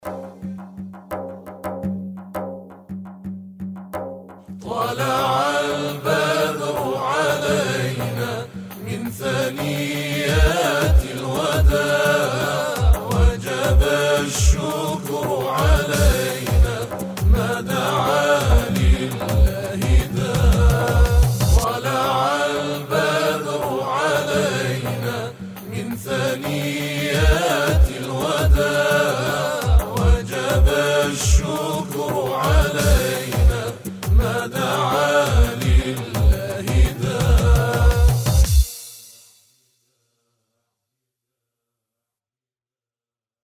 تواشیح:طلع البدر